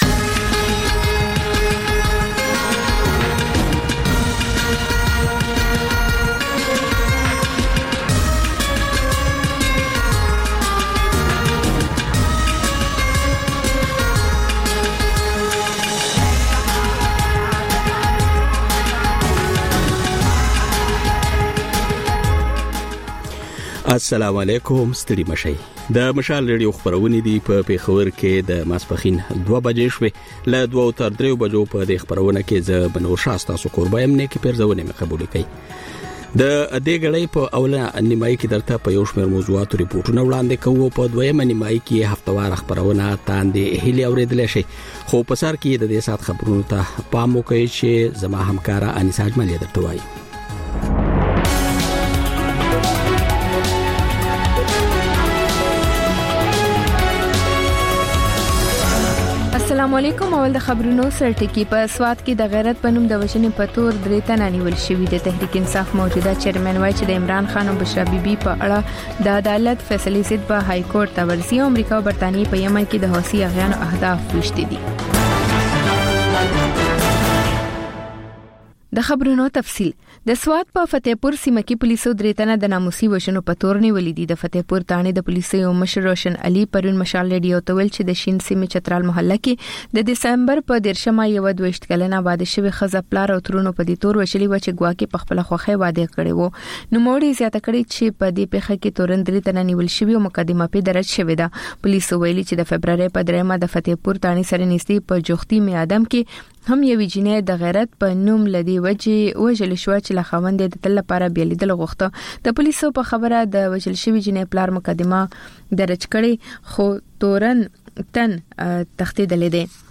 د مشال راډیو دویمه ماسپښینۍ خپرونه. په دې خپرونه کې لومړی خبرونه او بیا ځانګړې خپرونې خپرېږي.